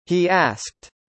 というフレーズでは、「長いe」の/i/と「短いa」の/æ/の間に小さな/y/を加えています。
これにより、単語の間にポーズ(一時停止)を取らなくても、両方の母音を完全に別々に発音することができます。